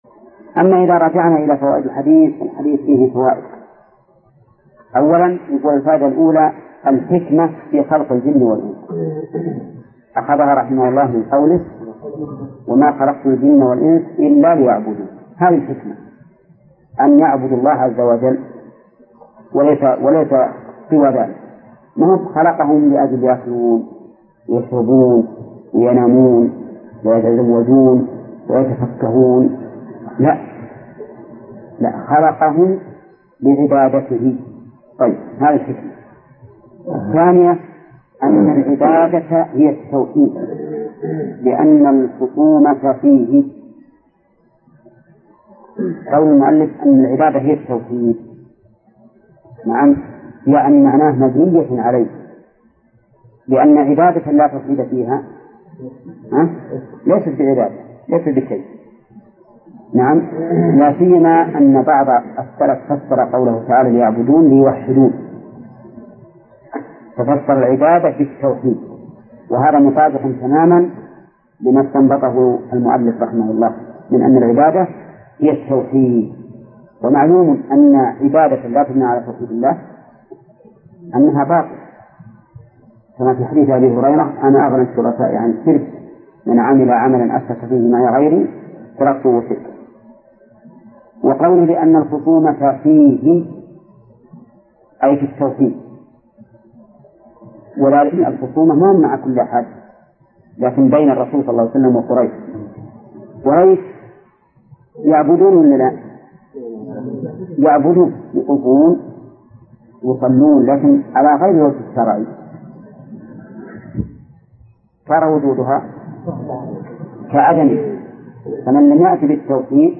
الدرس الثالث من صفحة: (49)، قوله: (فيه مسائل)، إلى صفحة: (68)، قوله: (وأن محمداً عبده ورسوله).